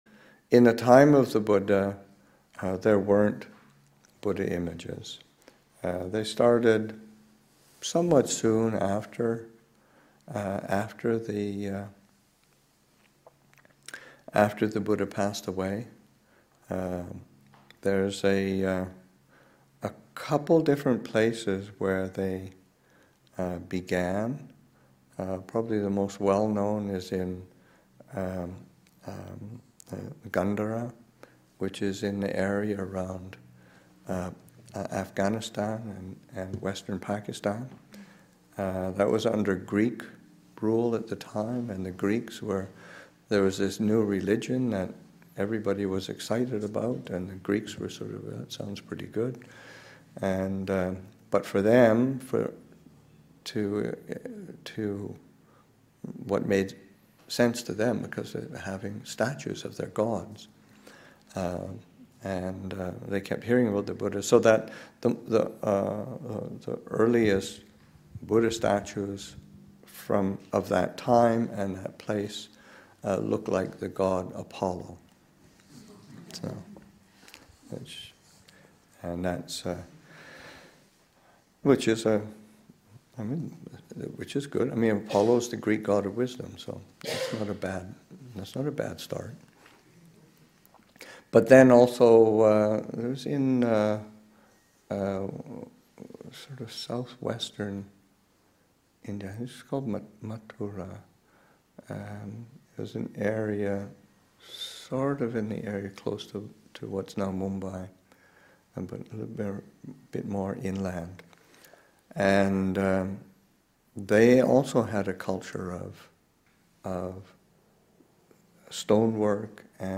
Story: The origin of Buddha images.